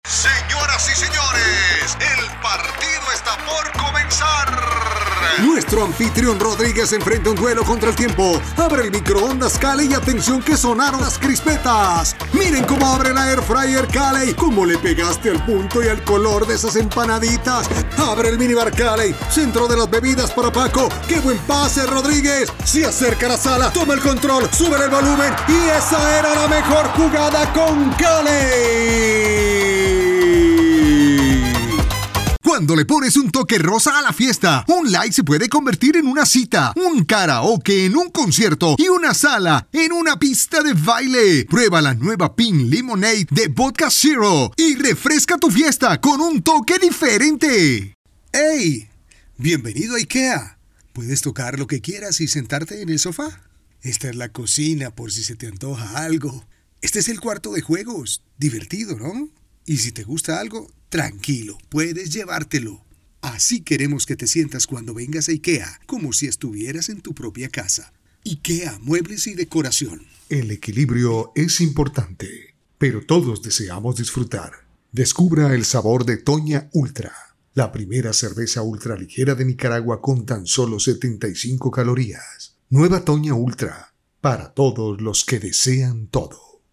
Micrófono Scarlett CM 25 Interfaz Focusrite Scarlett solo 4 Audífonos Scarlet SM 450 Adobe Audition
kolumbianisch
Sprechprobe: Werbung (Muttersprache):
My voice range is from 25 to 60 years old. My voice is warm, mature, energetic, happy, brassy, narrator tips.